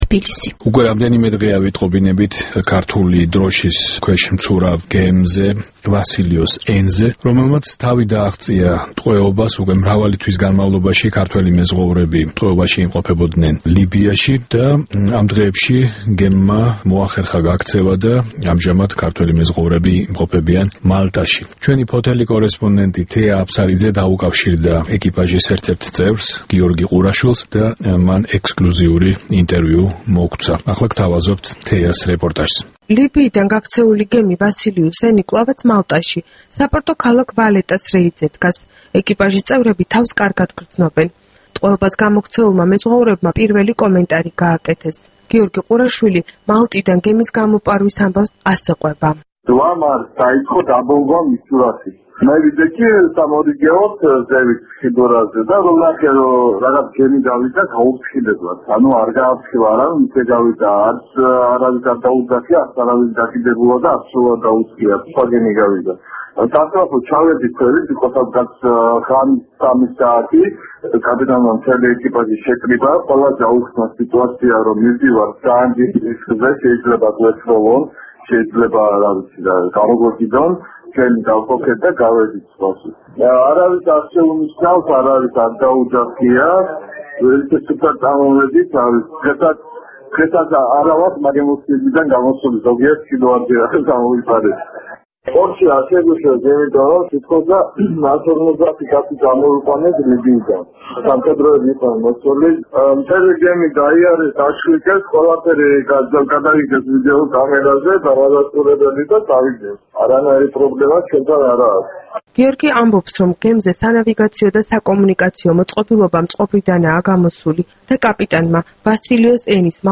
ექსკლუზიური ინტერვიუ "ვასილიოს N”-ის ეკიპაჟის წევრთან